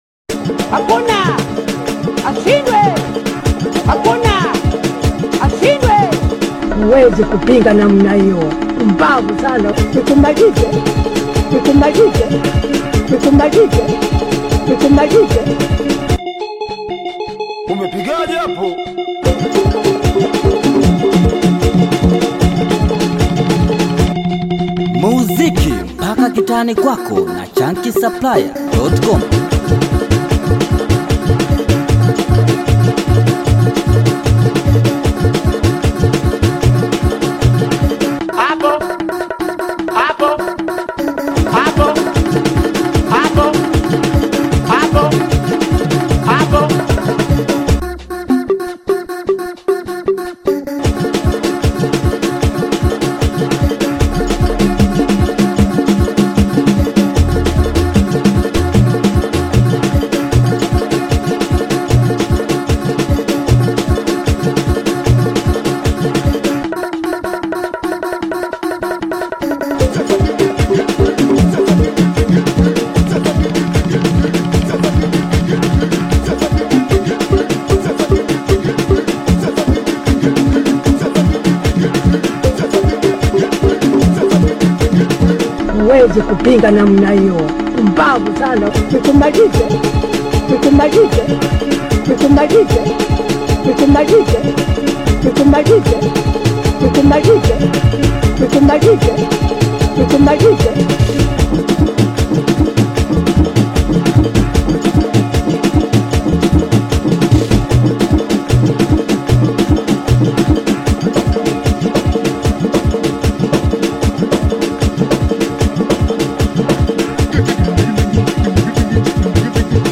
SINGELI BEAT